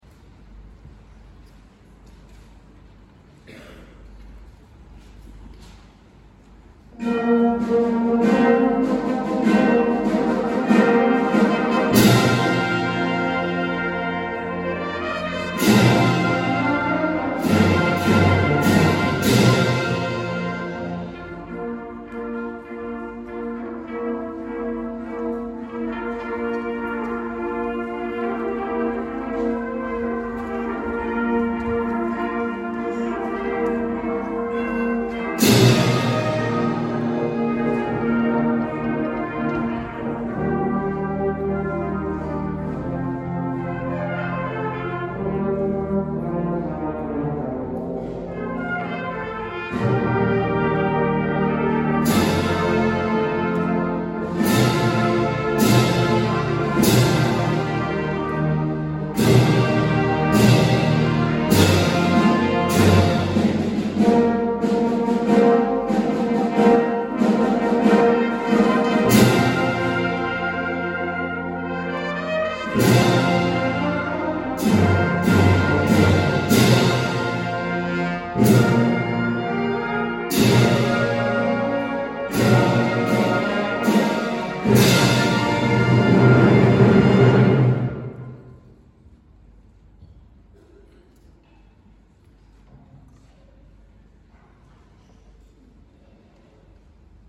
Christmas Bau-Balle Fanfare | Brass Ensemble